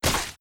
Flesh Hit2.wav